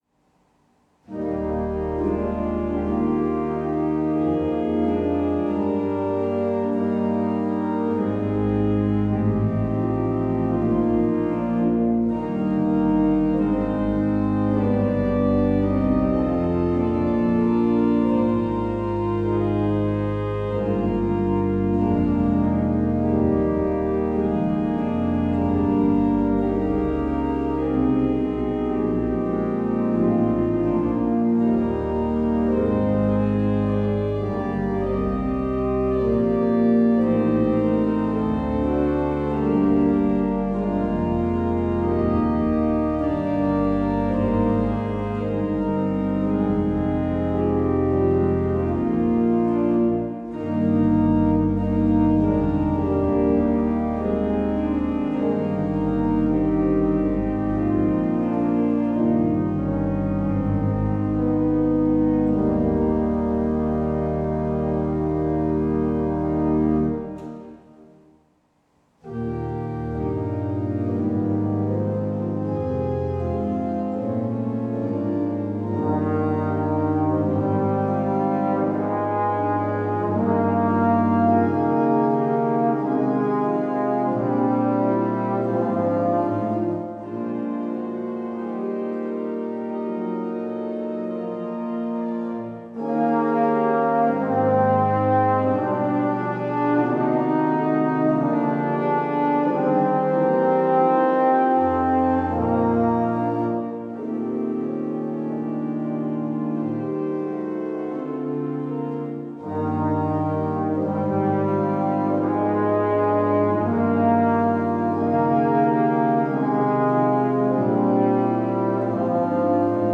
Die Aufnahme entstand in der Kirche Christus König.
Posaune
Orgel
OrgelPosaune.mp3